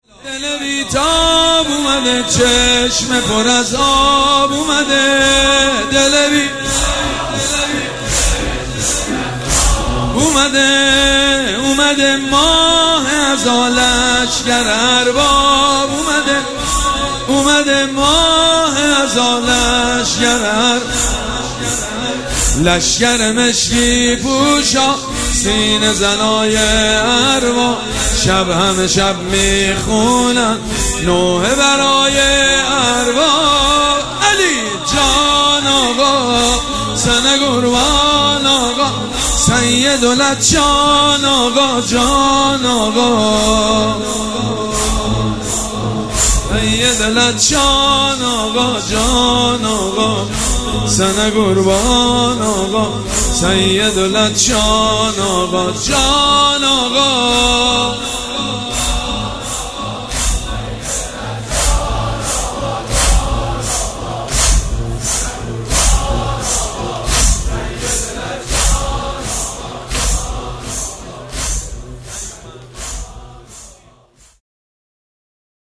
شب سوم مراسم عزاداری اربعین حسینی ۱۴۴۷
مداح
حاج سید مجید بنی فاطمه